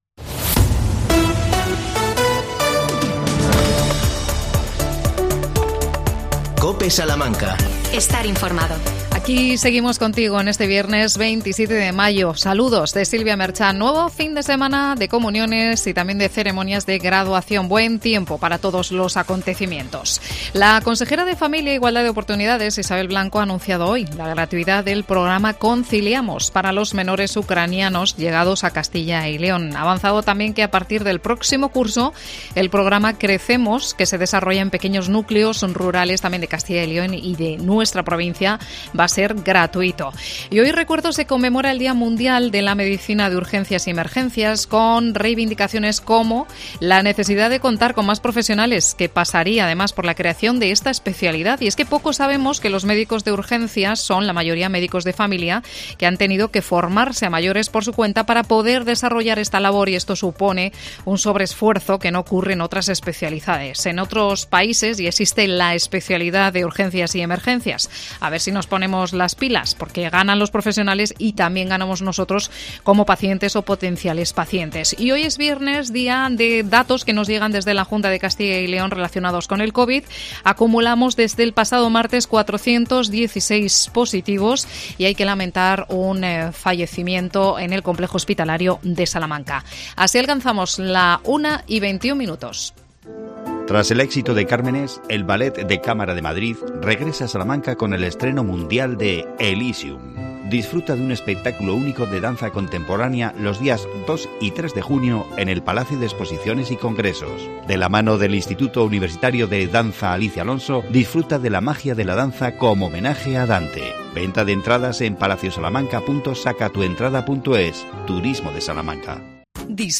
AUDIO: Charlamos con la alcaldesa de Castellanos de Moriscos Victoria Manjón. Esta tarde se inaugura el espacio de coworking.